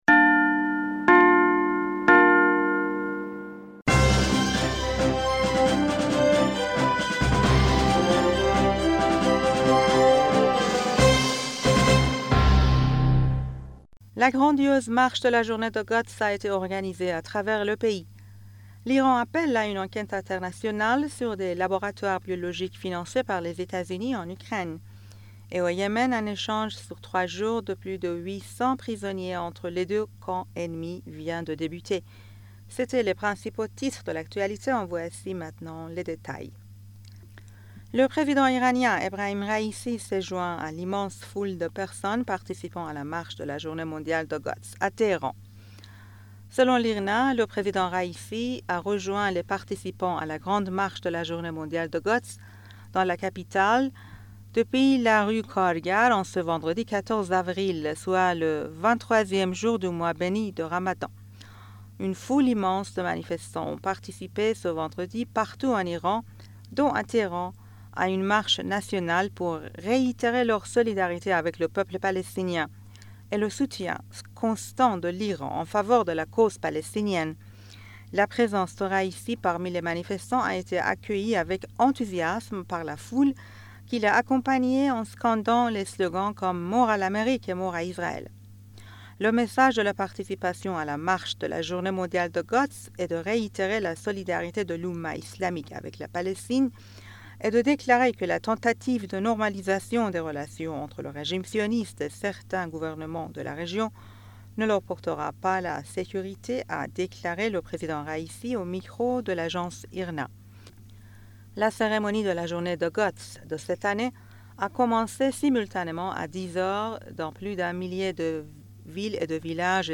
Bulletin d'information du 14 Avril 2023